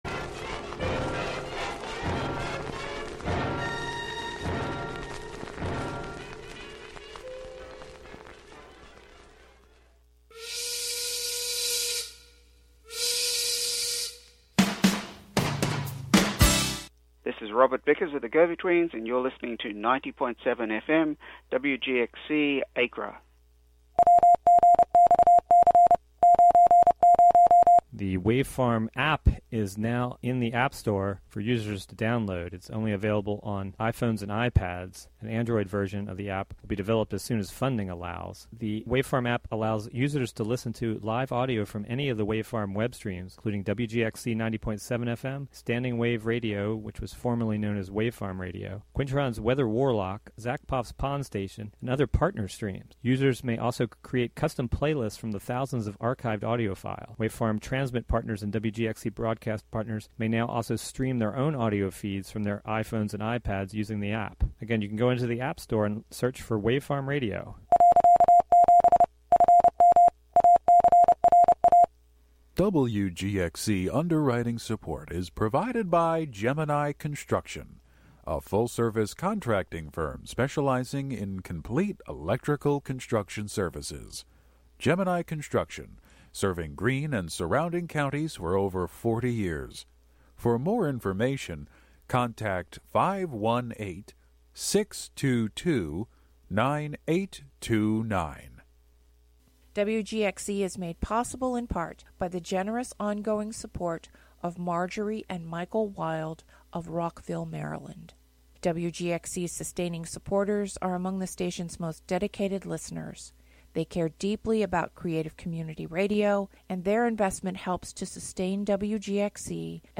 5am Monthly program featuring music and interviews.